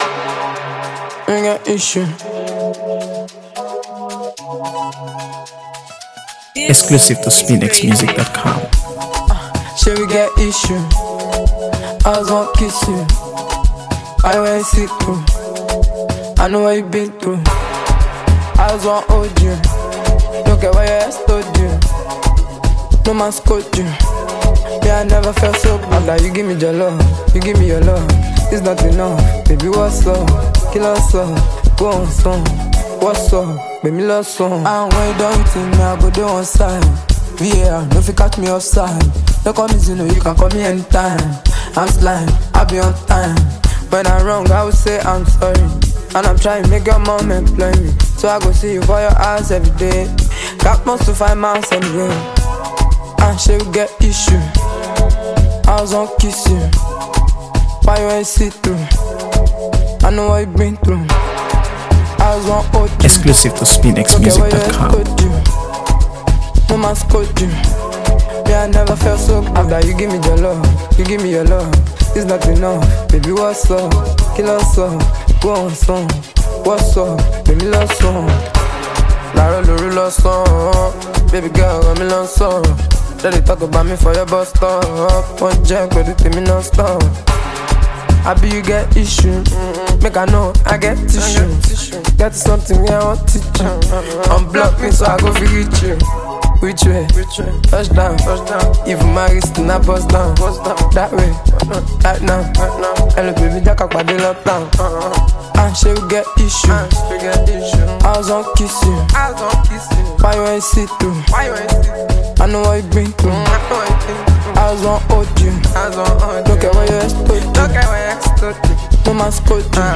AfroBeats | AfroBeats songs
Known for his smooth vocals and captivating melodies
rich, rhythmic, and undeniably danceable